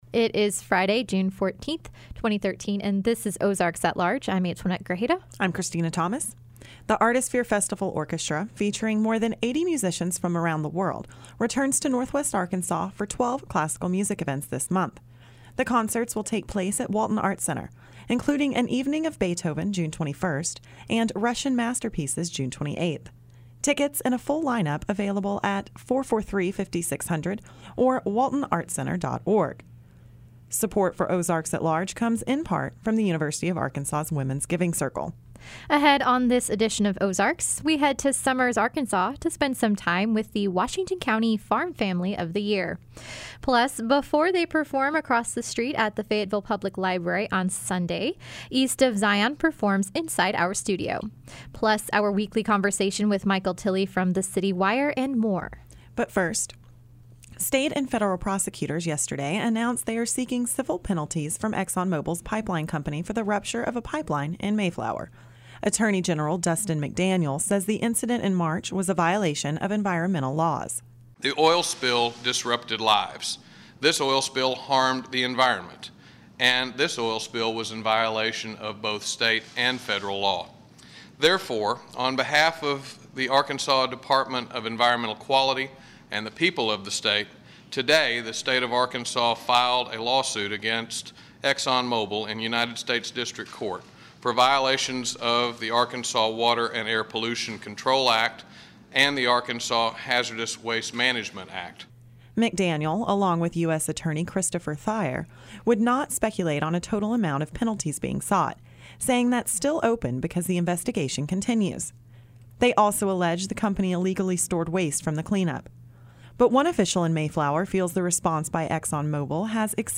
Ahead on this edition of Ozarks, we head to Summers, Arkansas to spend some time with the Washington County Farm Family of the Year. Plus, East of Zion performs inside our studio.